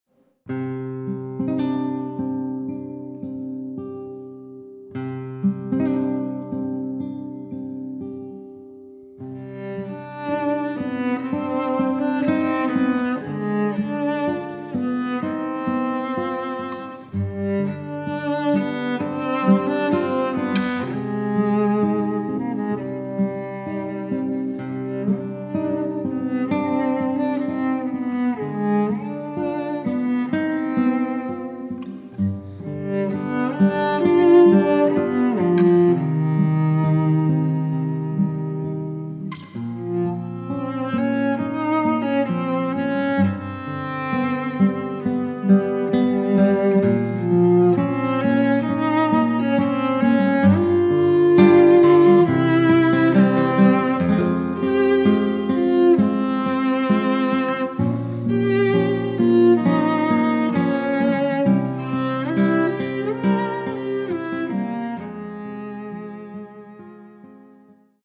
und Computersounds, eingängig und atmosphärisch dicht.